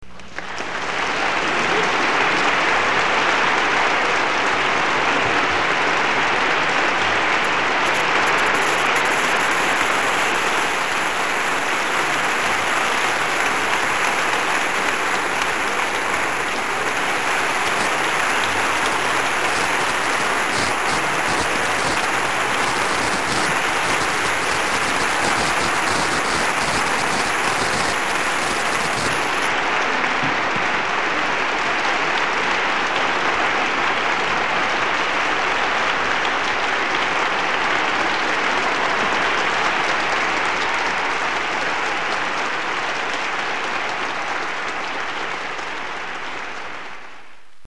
aplaudiments.mp3